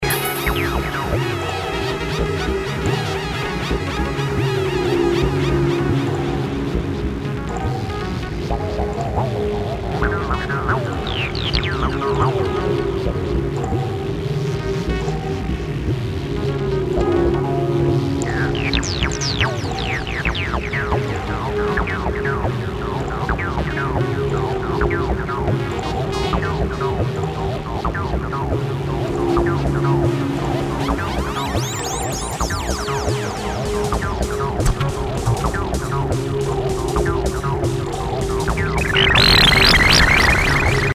Ambient electronics
with a strong Detroit feel to it.